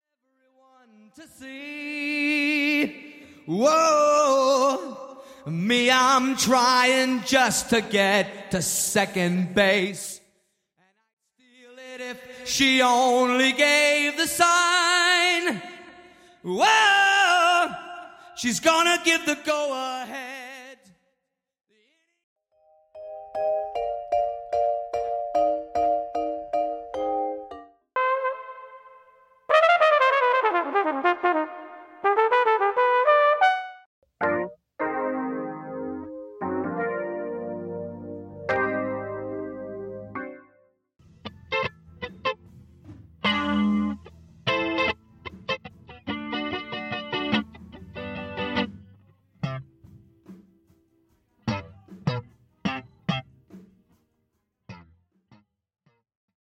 (Studio Jazz Strings Stem)
(Studio Piano Stem)
(Studio Trumpets Stem)